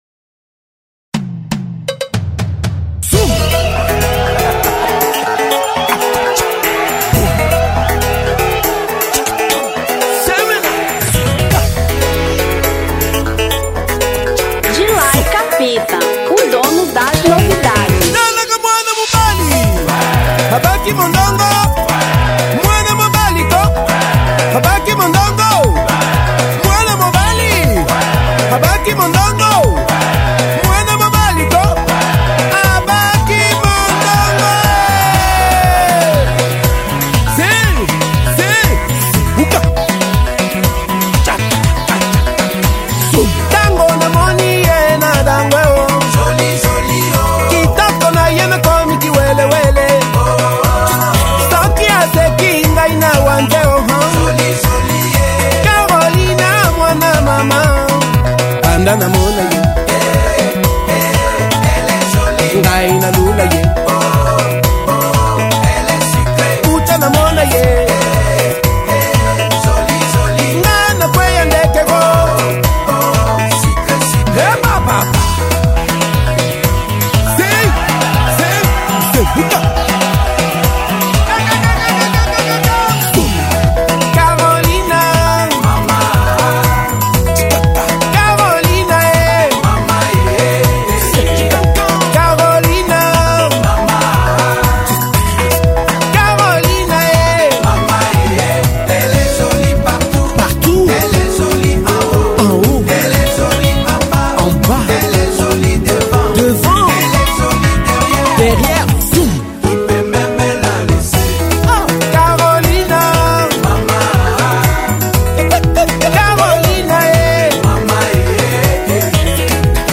Rumba 2003